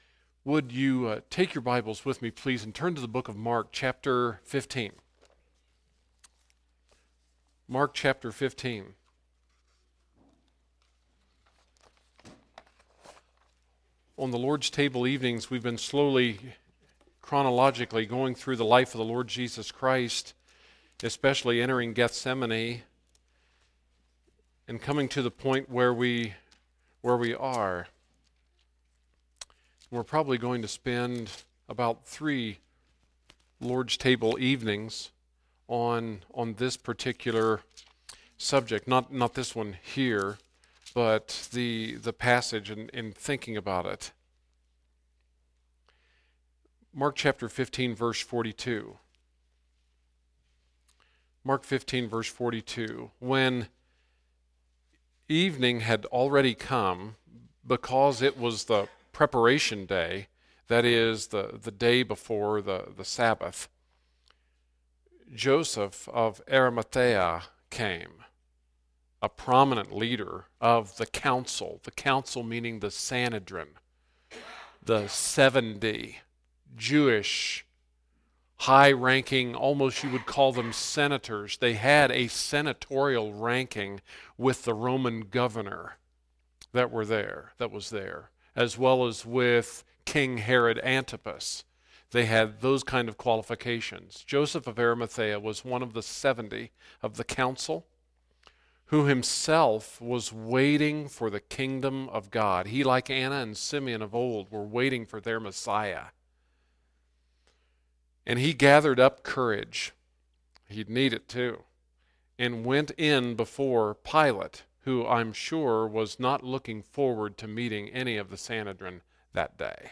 Evening Service